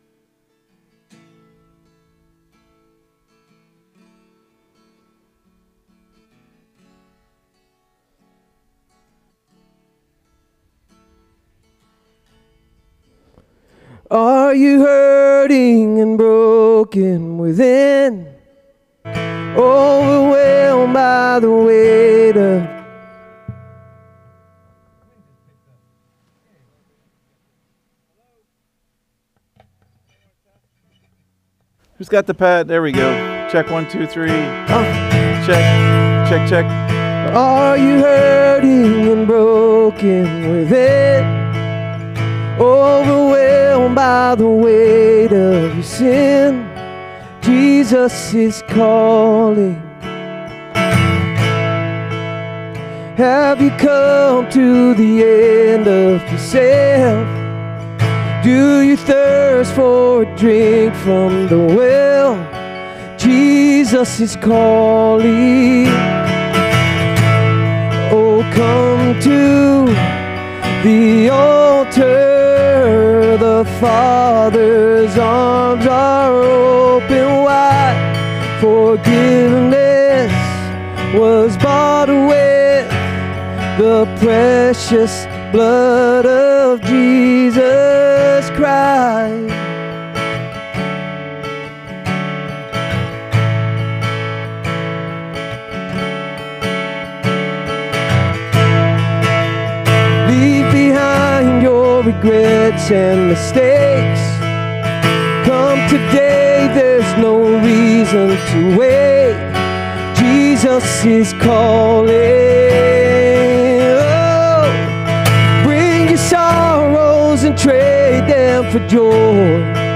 SERMON DESCRIPTION God visits Abraham with a promise—showing He sees, cares, and fulfills what He says.